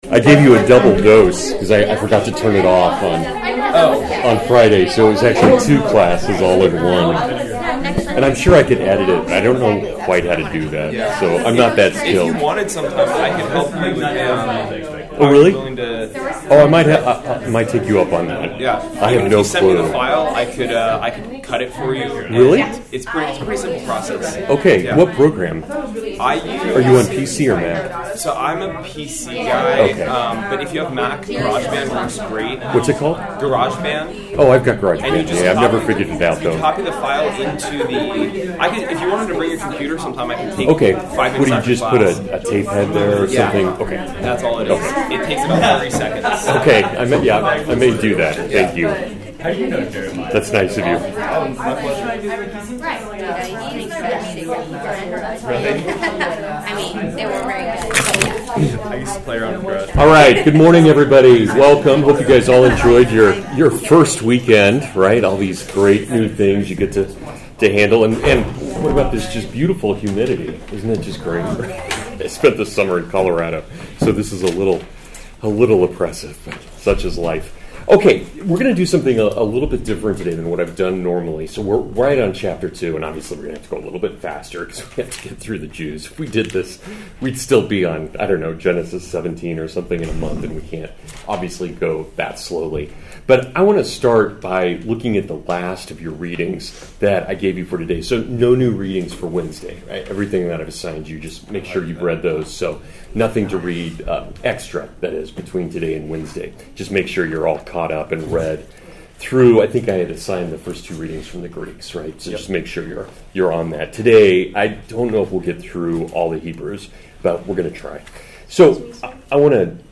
Full lecture from my third Western Heritage class of the semester, covering the stories of Abraham and David.